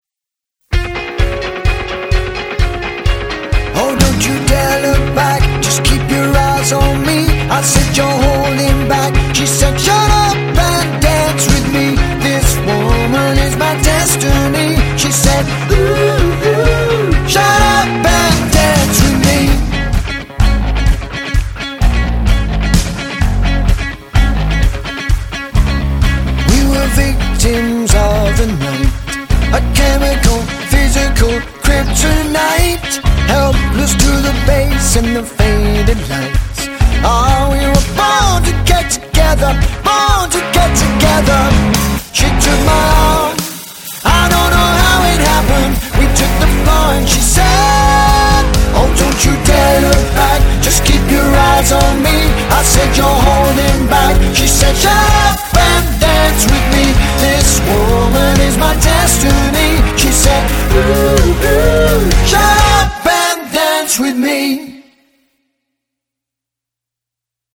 Professional Party Band